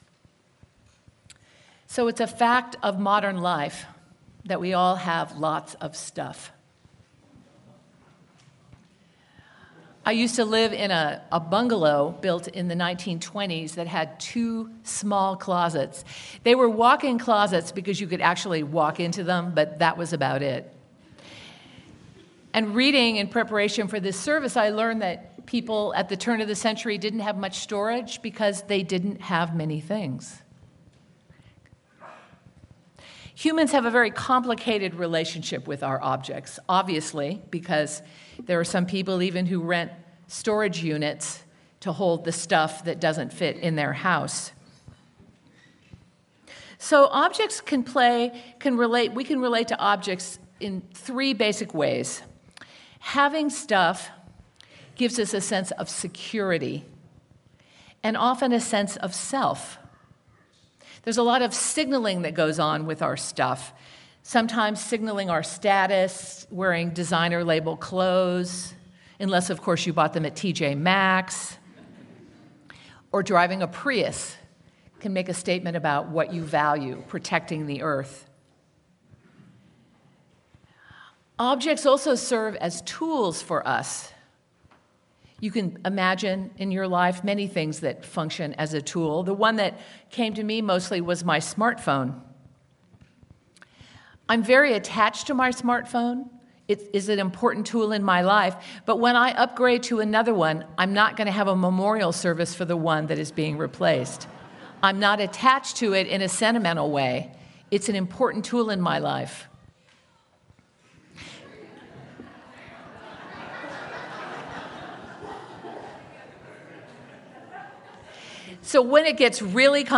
Sermon-What-We-Leave-Behind.mp3